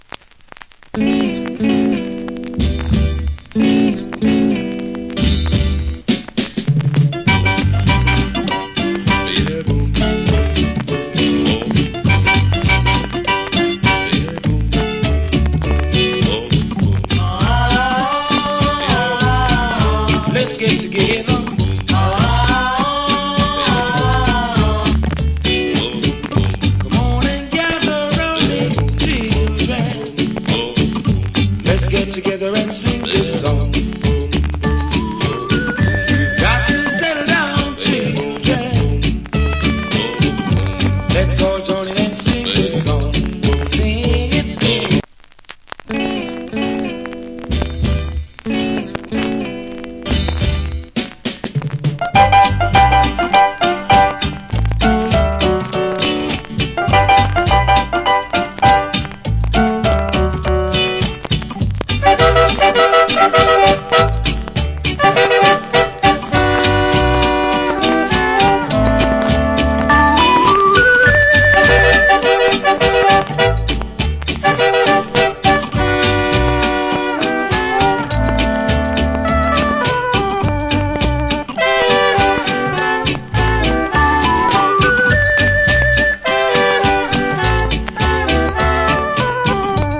Great Early Reggae Vocal.